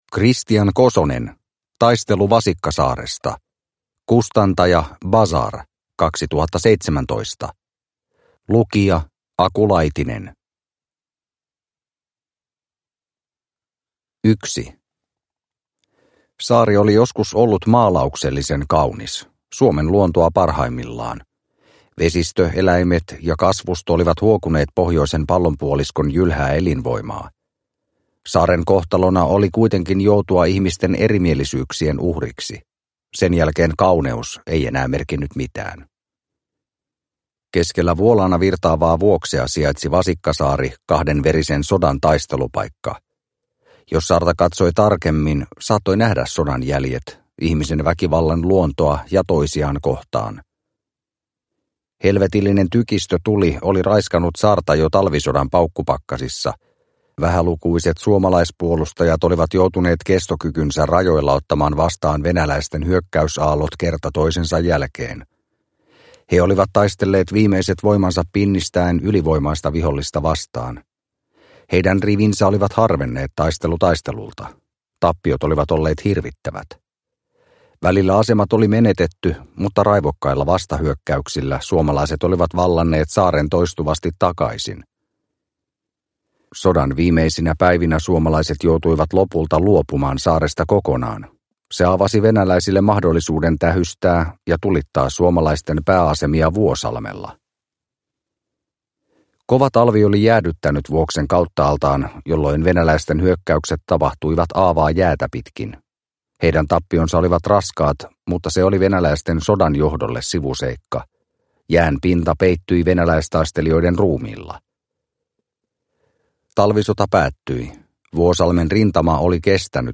Taistelu Vasikkasaaresta – Ljudbok – Laddas ner